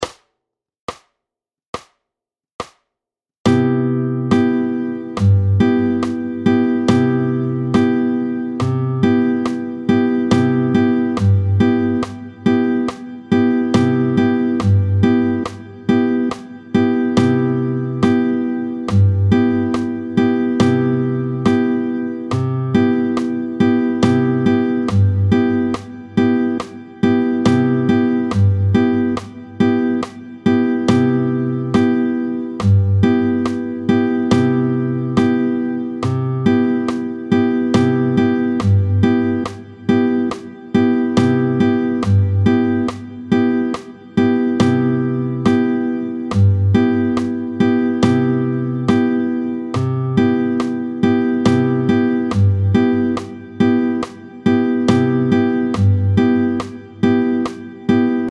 30-03 Motif de base en C7M, tempo 70